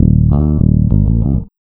SPD140BASS-R.wav